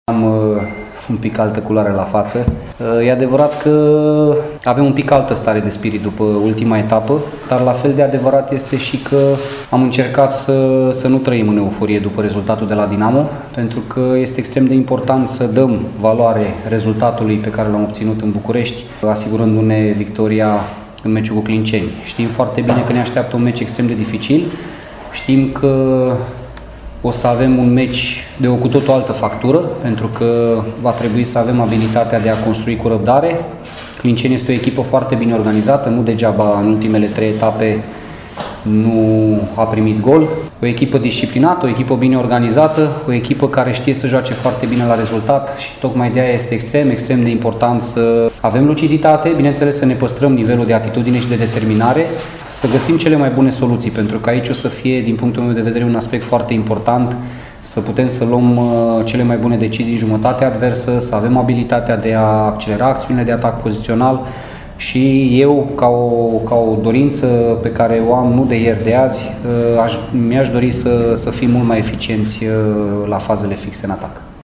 UTA are moral bun după Victoria cu Dinamo (1-0, în Ștefan cel Mare), dar antrenorul Laszlo Balint spune că meciul cu Clinceniul va fi diferit: